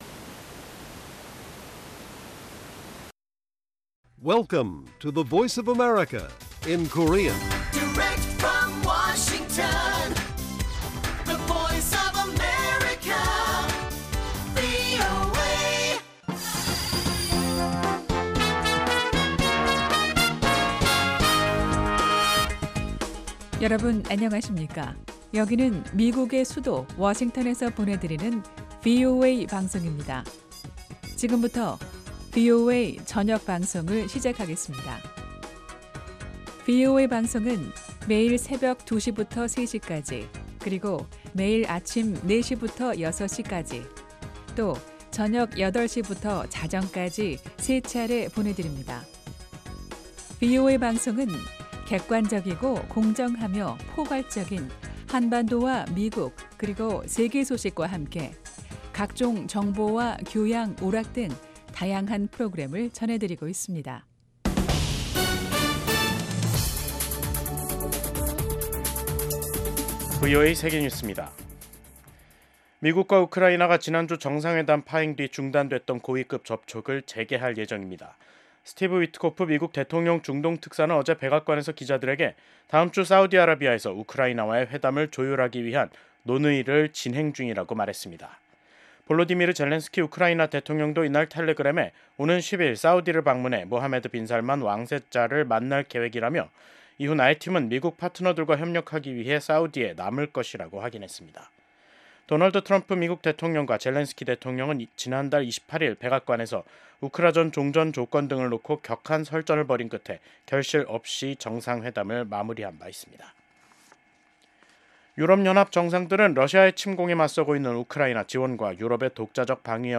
VOA 한국어 간판 뉴스 프로그램 '뉴스 투데이', 2025년 3월 7일 1부 방송입니다. 미국 도널드 트럼프 대통령의 핵심 측근이 북러 밀착을 지적하며 한반도 전쟁 시 러시아군이 참전할 수 있다고 말했습니다. 미국 재무부 부장관 지명자는 트럼프 행정부의 관세 정책이 상호주의에 중점을 두고 있다고 밝혔습니다. 미국 전문가들은 도널드 트럼프 대통령이 의회 연설에서 미국의 조선업 부활을 천명한 것이 한국에 기회가 될 수 있다고 진단했습니다.